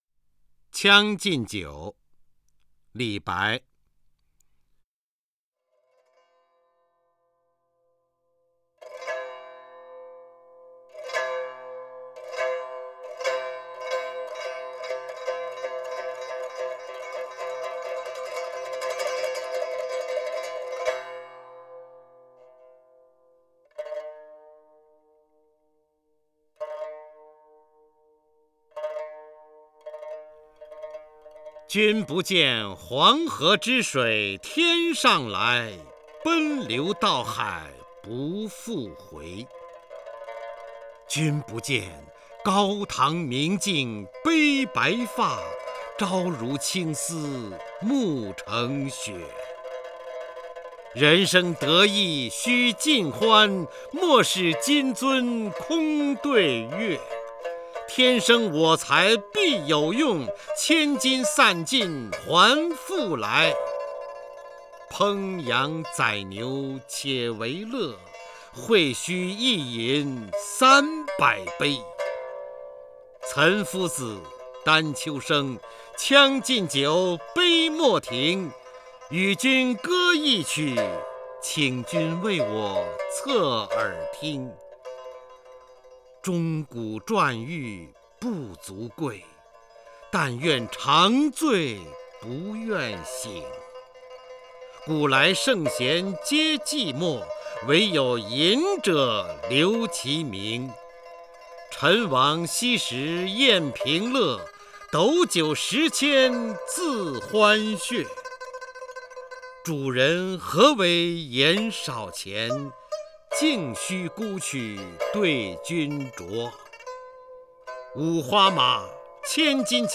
首页 视听 名家朗诵欣赏 方明
方明朗诵：《将进酒》(（唐）李白)
JiangJinJiu_LiBai(FangMing).mp3